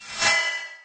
whoosh_fast_1.ogg